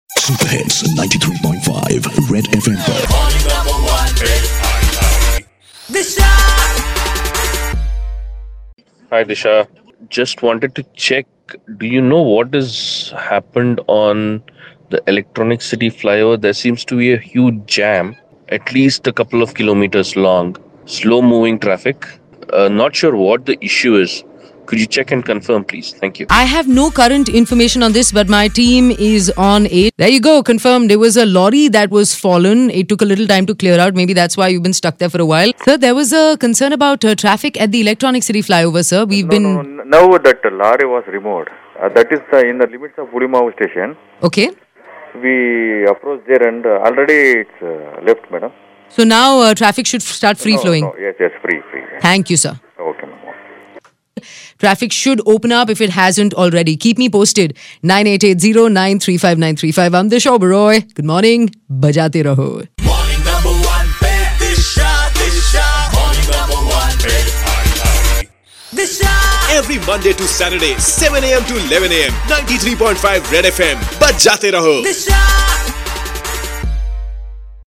Listener called to ask the reason for huge traffic at Electronic City Flyover and we called the Traffic police for the update